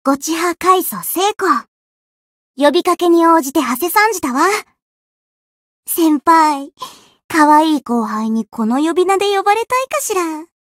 灵魂潮汐-青蒿-人偶初识语音.ogg